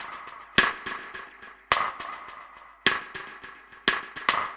Index of /musicradar/dub-drums-samples/105bpm
Db_DrumKitC_EchoSnare_105-03.wav